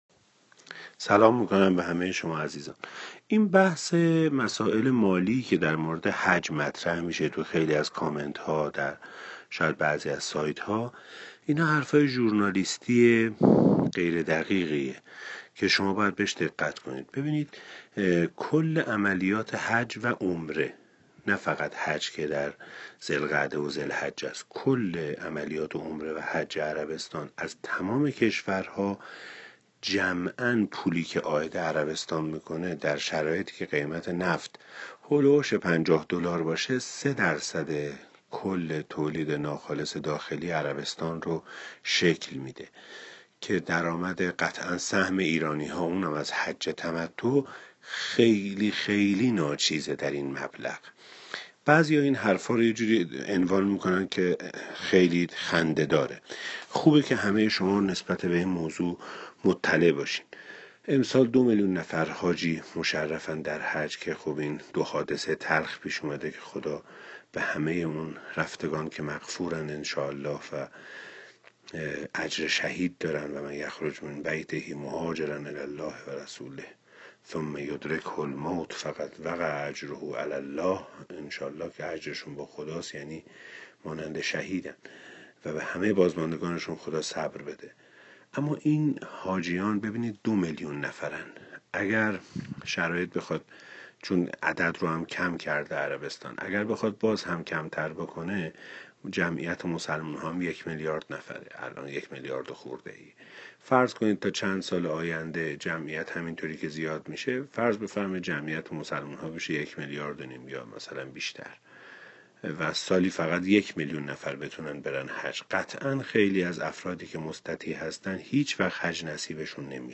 یادداشت شفاهی